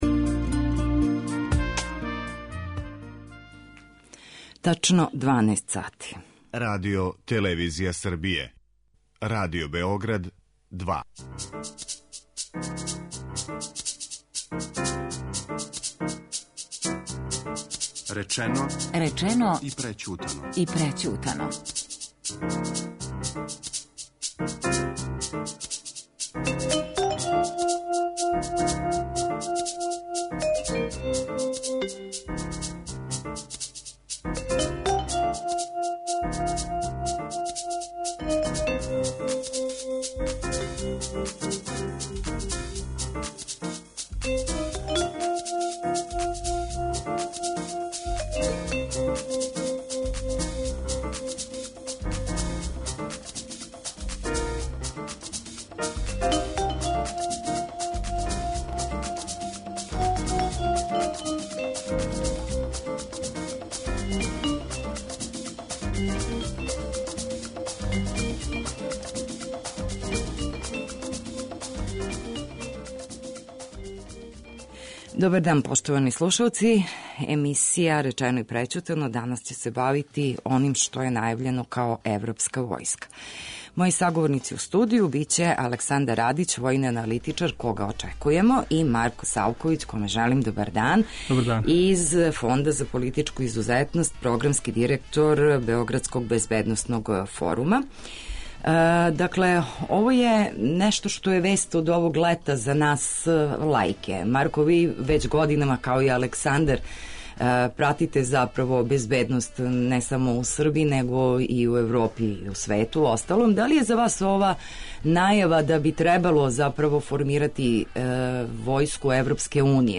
Гости у студију су